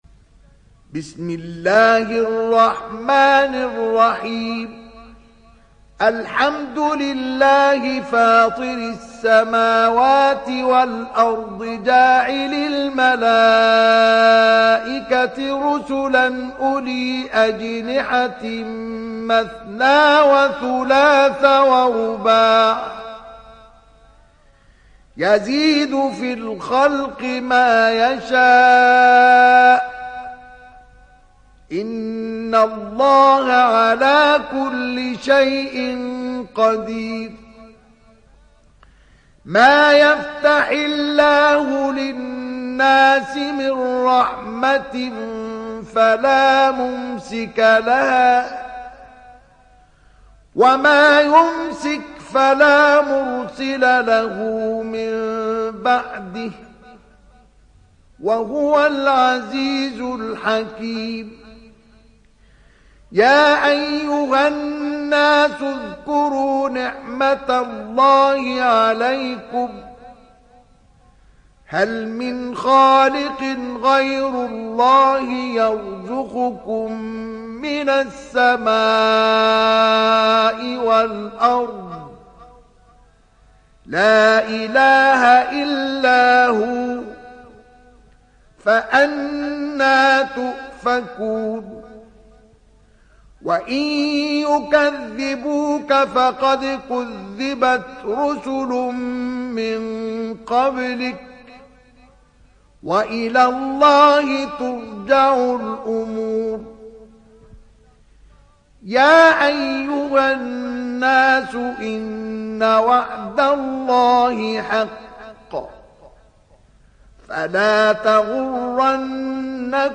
تحميل سورة فاطر mp3 بصوت مصطفى إسماعيل برواية حفص عن عاصم, تحميل استماع القرآن الكريم على الجوال mp3 كاملا بروابط مباشرة وسريعة